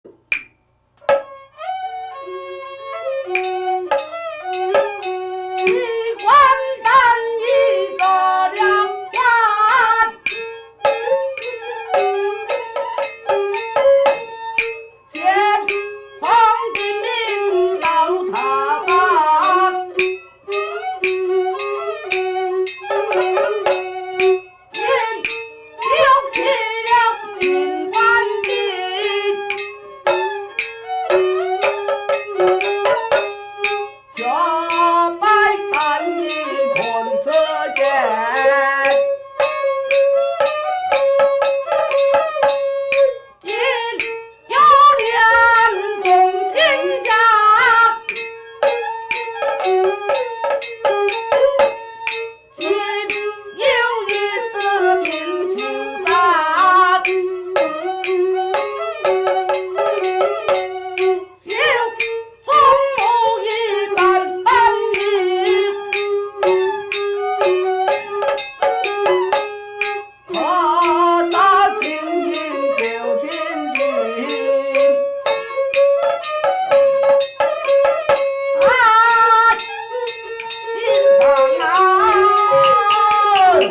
戲曲 - 蘇武牧羊選段（四空門） | 新北市客家文化典藏資料庫